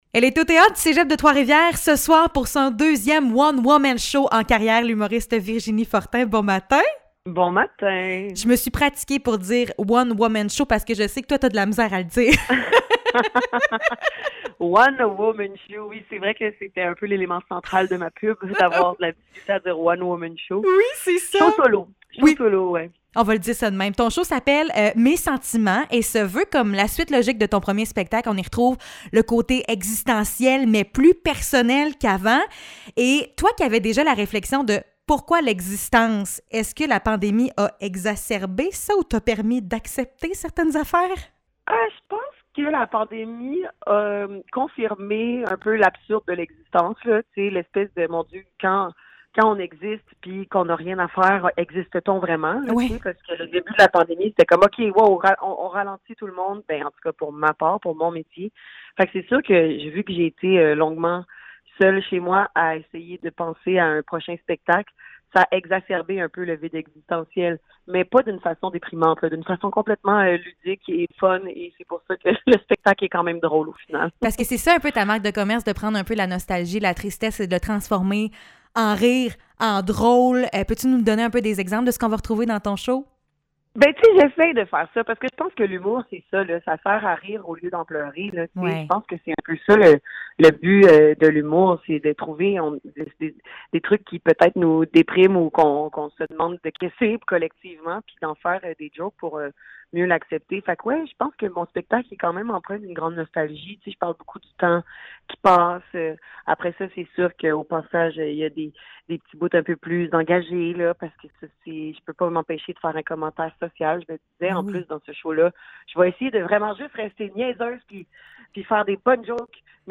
Entrevue avec l’humoriste Virginie Fortin (1er avril 2022)